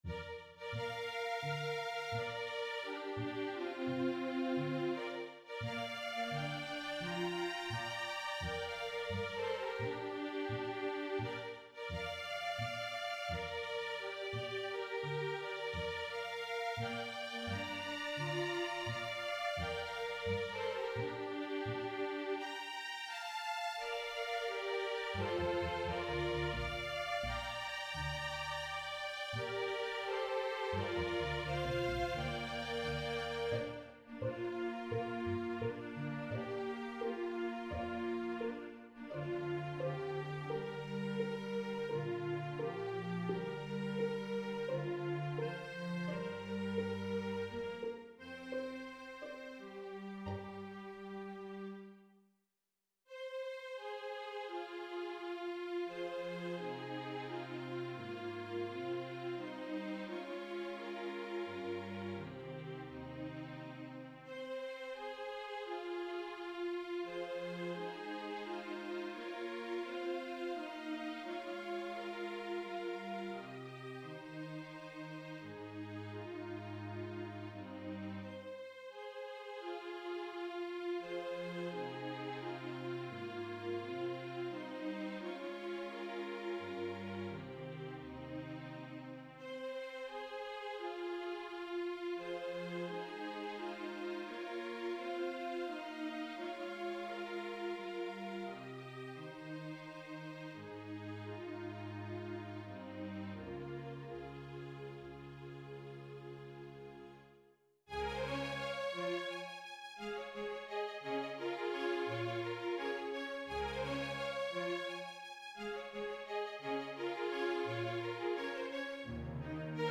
Petite Undertaking for String Orchestra
Hi, Here's string piece written with middle school students in mind. Nothing spectacular ... however, somewhat pleasant I think.